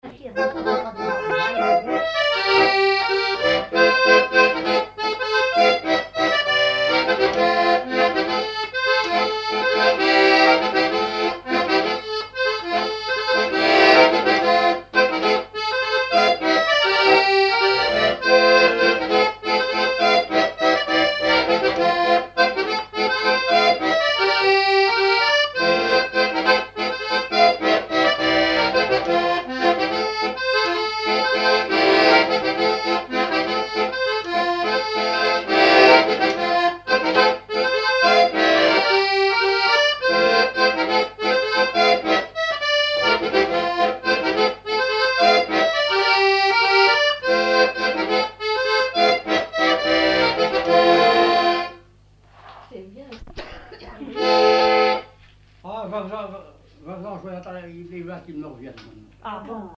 Localisation Noirmoutier-en-l'Île (Plus d'informations sur Wikipedia)
Fonction d'après l'analyste danse : mazurka ;
Catégorie Pièce musicale inédite